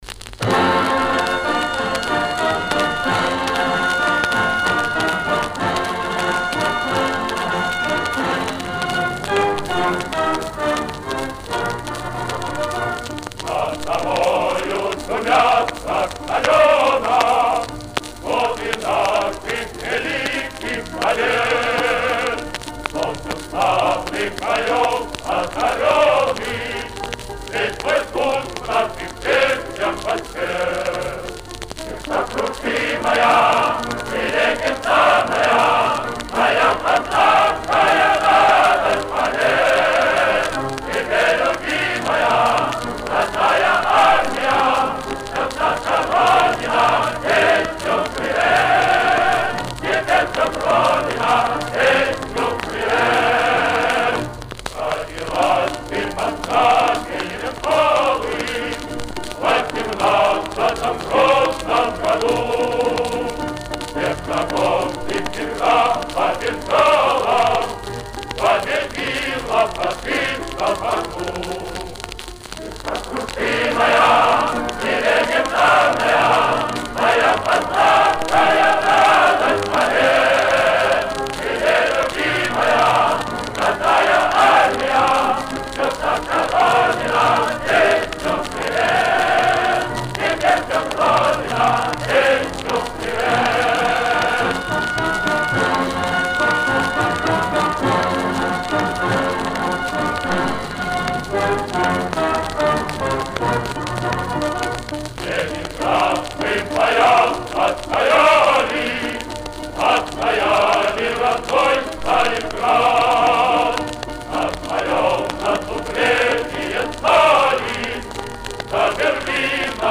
Послевоенное исполнение, текст частично переработан.